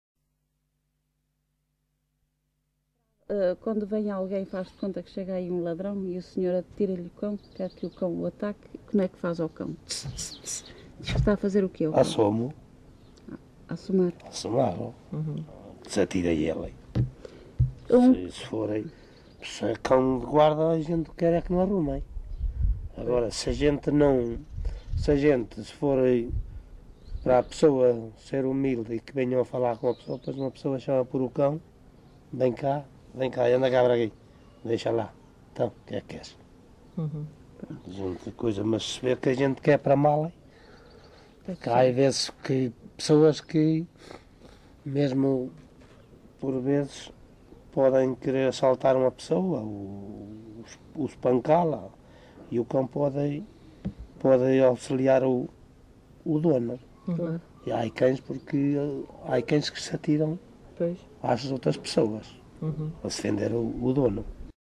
LocalidadeOuteiro (Bragança, Bragança)